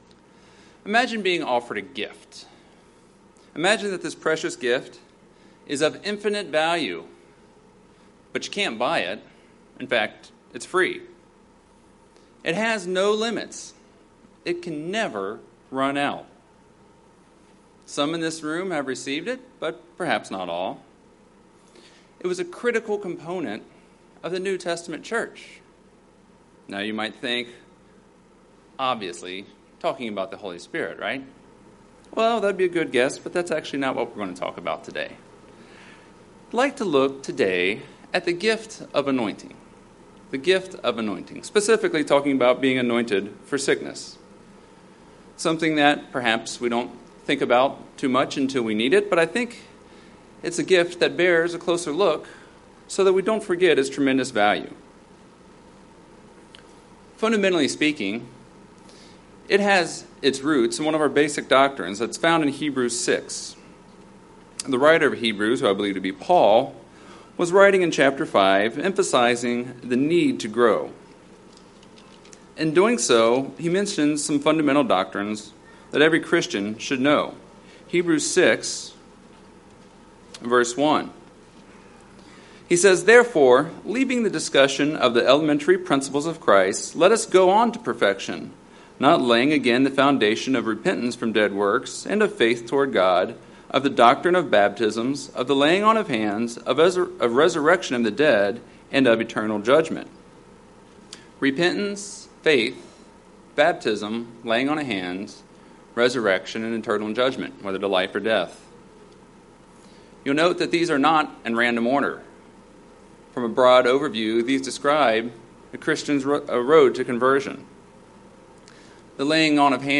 What is anointing, and why should we do it? This sermon gives a basic but comprehensive overview of anointing.
Given in Cincinnati East, OH